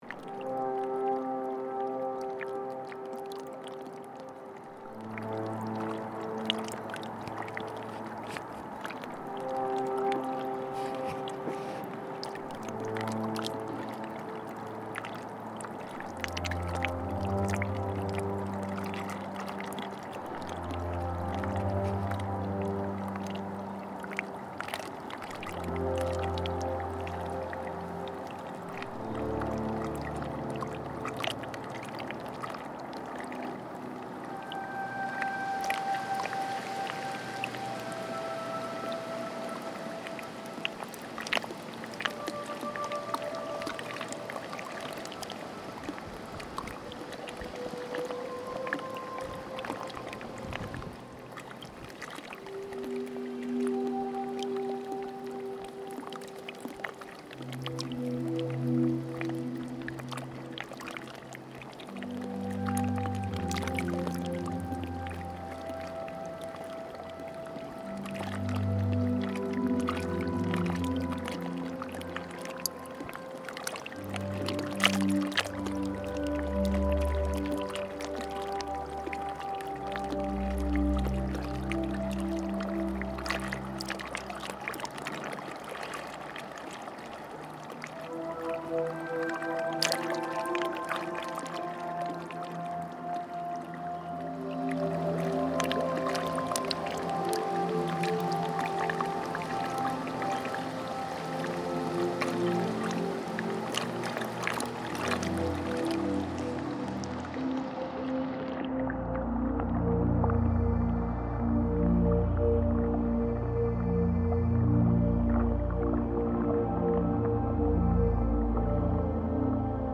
Something relaxing and comfortable about this space.